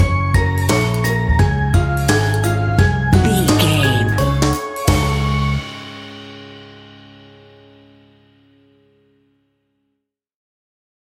Ionian/Major
D♭
kids instrumentals
fun
childlike
cute
happy
kids piano